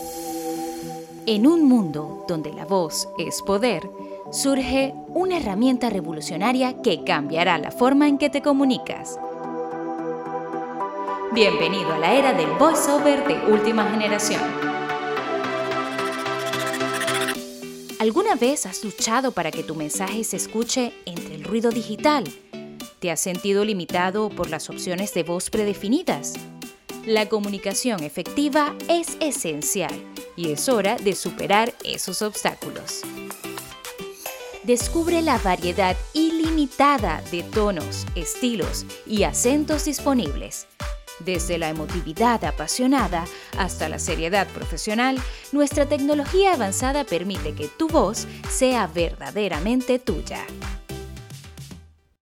Joven, Natural, Amable, Cálida, Suave
Corporativo